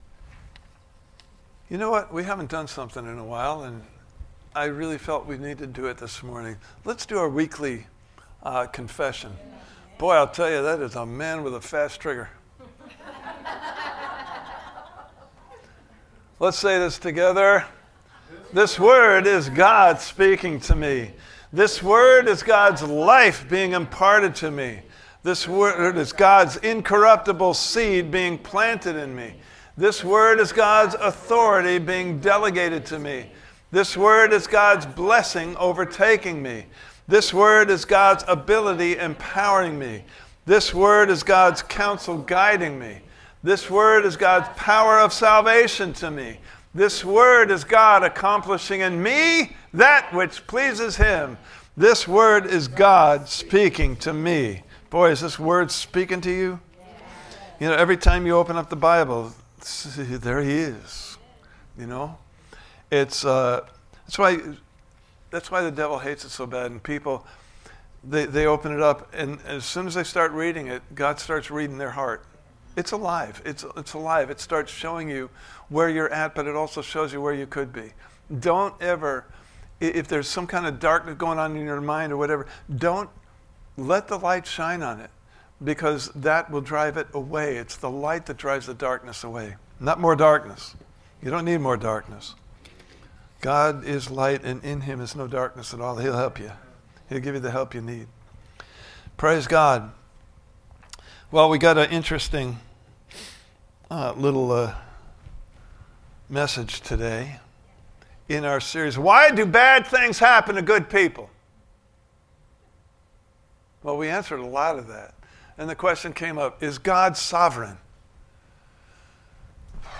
Service Type: Sunday Morning Service « Part 3: All Things Work Together for Good …?